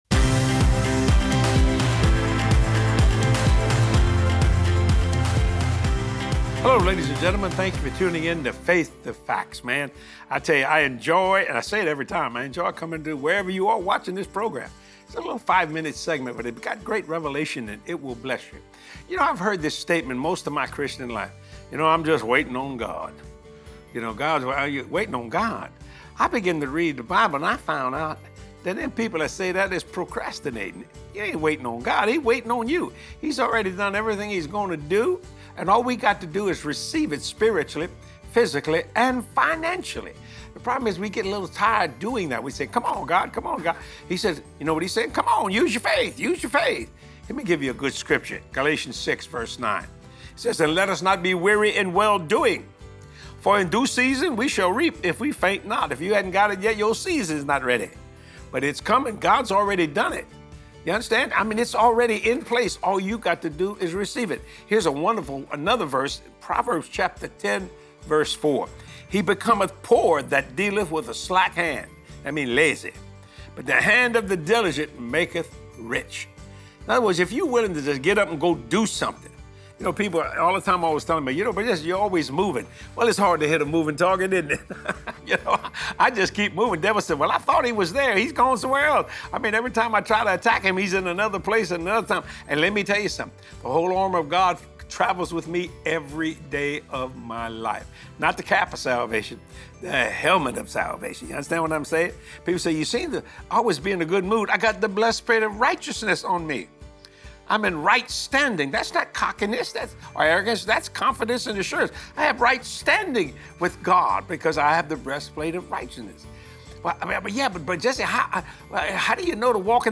Watch this motivating teaching with Jesse and be encouraged to start obeying God while moving in His perfect path for your life!